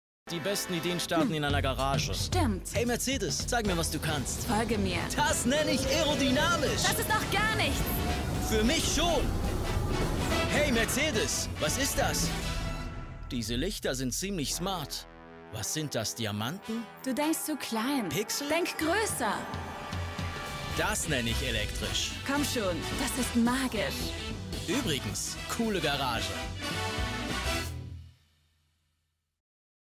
Allemand (Suisse)
Démo commerciale
Neumann TLM 103, Adobe Audition, homestudio